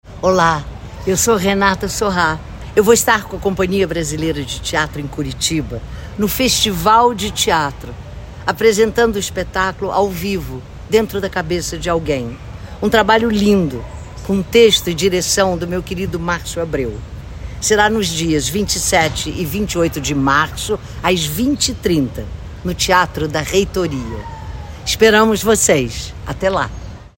Renata Sorrah fala sobre esta nova parceria com a Companhia Brasileira, iniciada em 2012 com a peça “Esta Criança”.